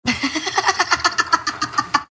Chanel West Coast Laugh